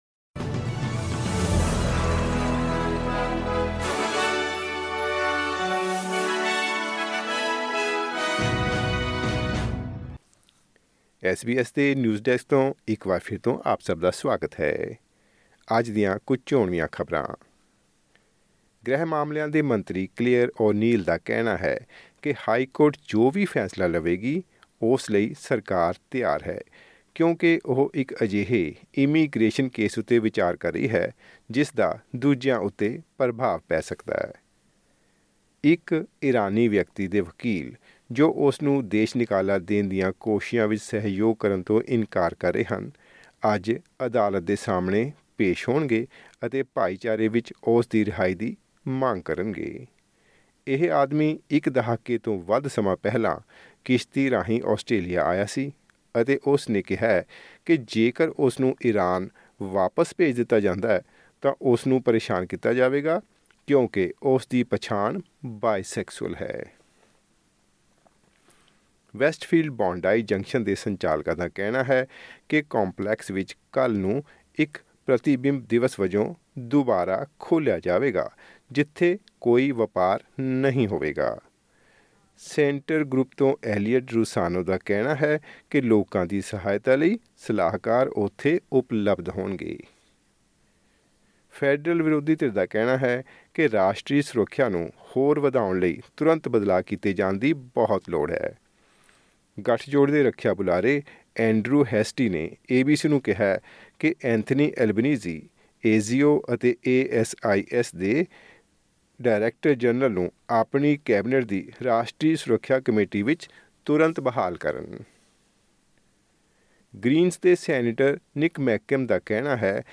ਐਸ ਬੀ ਐਸ ਪੰਜਾਬੀ ਤੋਂ ਆਸਟ੍ਰੇਲੀਆ ਦੀਆਂ ਮੁੱਖ ਖ਼ਬਰਾਂ: 17 ਅਪ੍ਰੈਲ, 2024